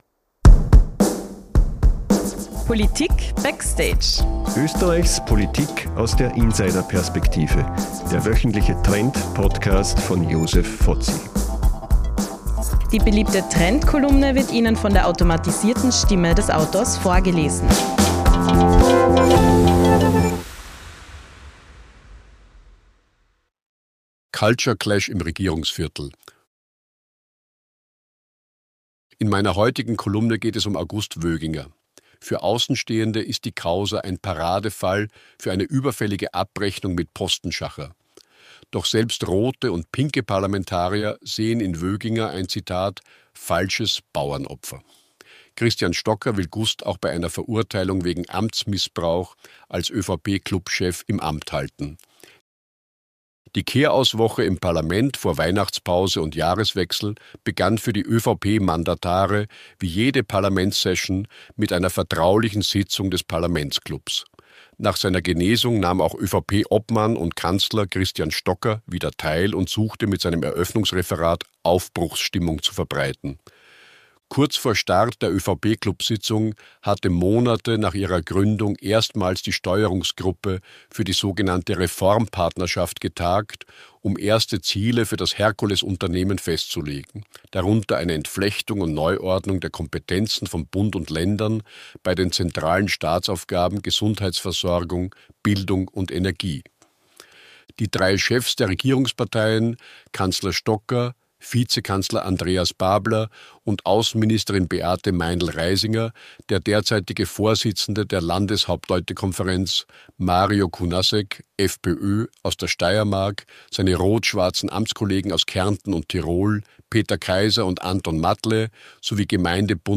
Wie jede Woche erzählt Ihnen die KI-generierte Stimme